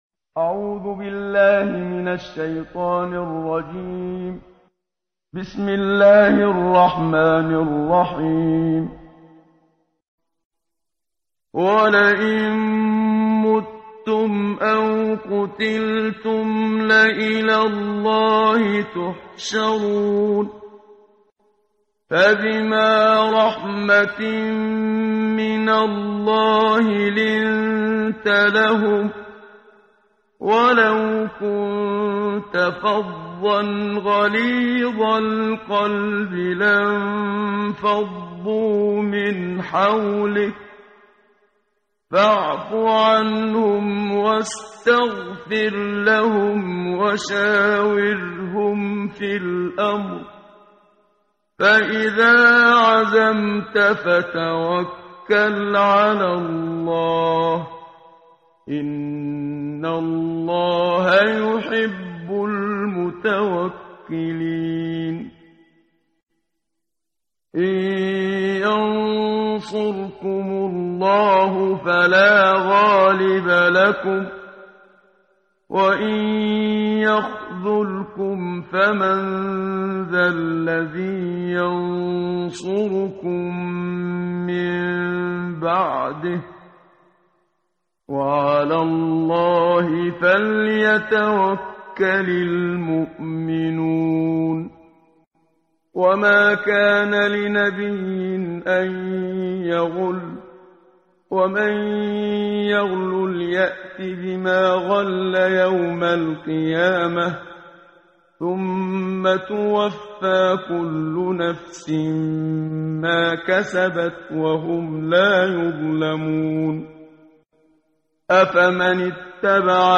قرائت قرآن کریم ، صفحه 71، سوره مبارکه آلِ عِمرَان آیه 158 تا 165 با صدای استاد صدیق منشاوی.